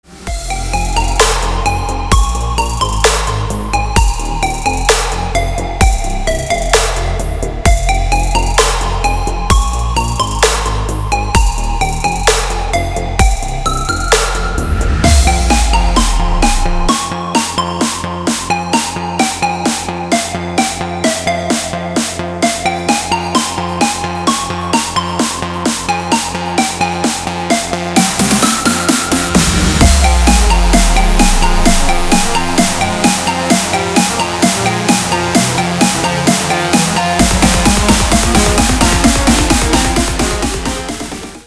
• Качество: 320, Stereo
громкие
dance
Electronic
электронная музыка
без слов
Trance